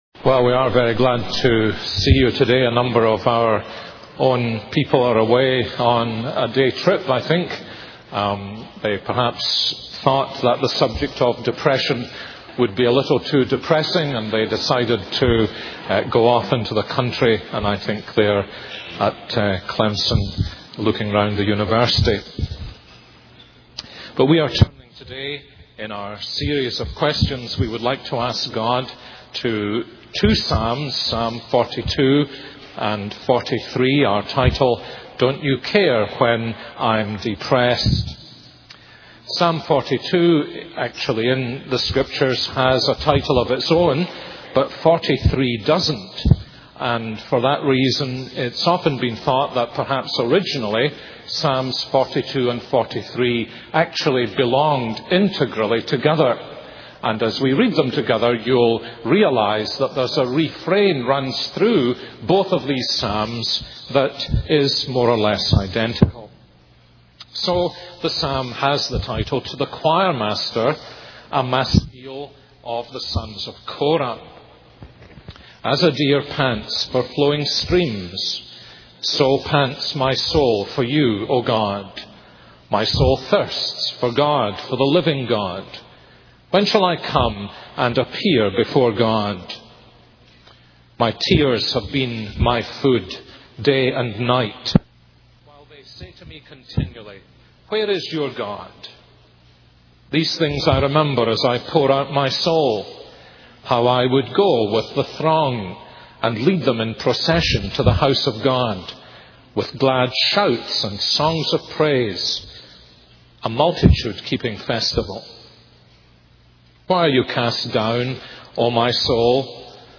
This is a sermon on Psalm 42-43.